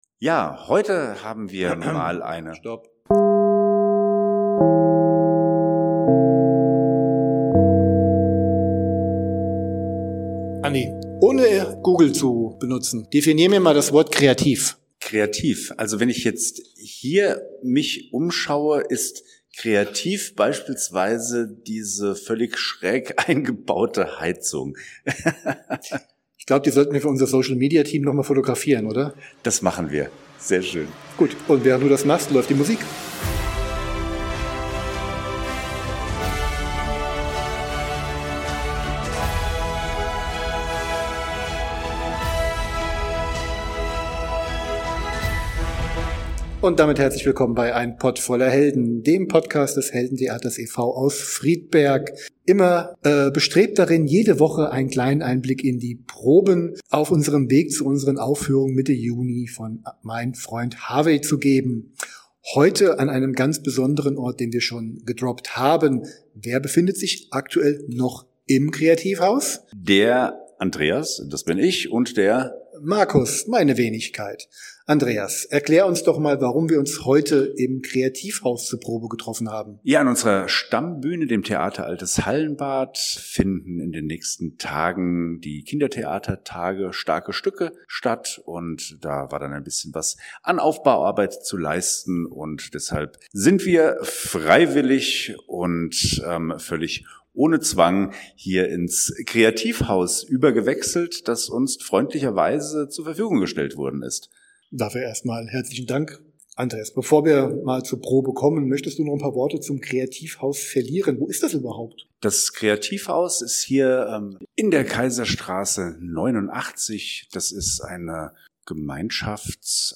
Wo Geschlechter sich friedlich tauschen, da kann ein kreativer Spirit nicht weit weg sein... Ist es da Zufall oder Chiffre, das wir für die diesmalige Probe in Kreativhaus auf der Kaiserstraße in Friedberg umgezogen sind.